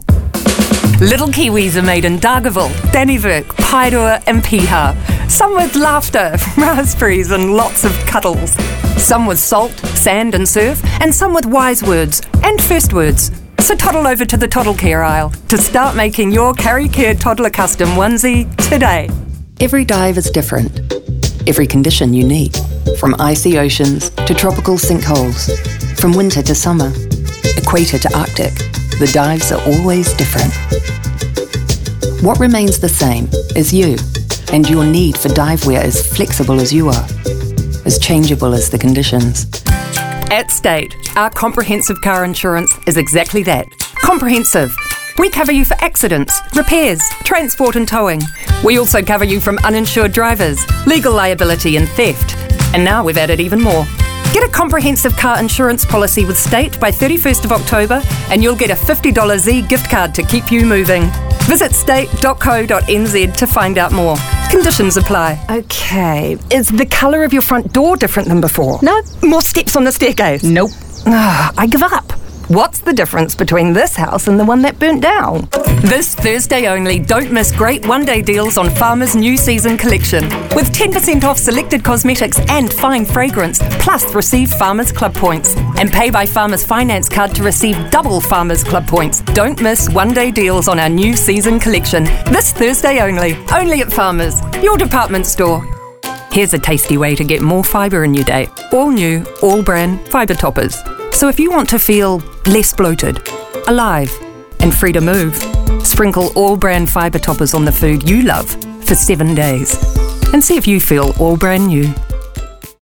Demo
Adult, Young Adult
English | New Zealand
international english
comedic
friendly
warm
well spoken